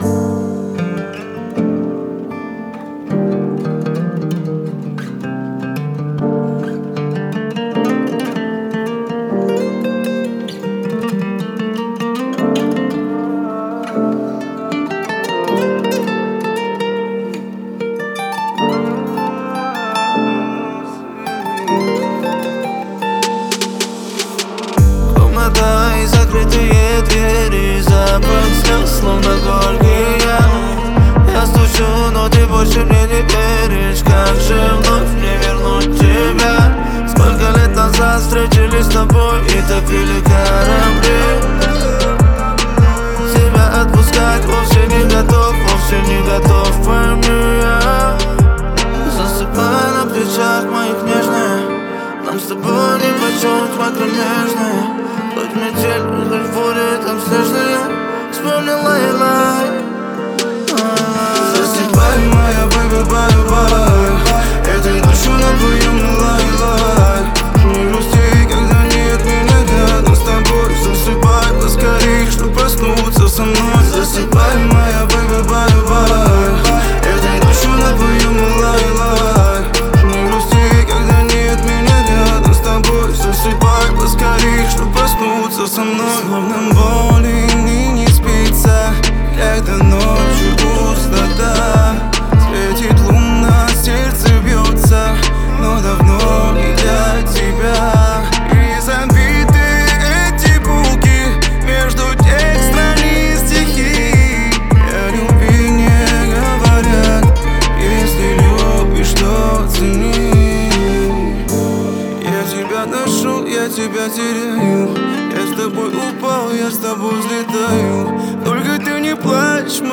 это трек в жанре поп с элементами фолка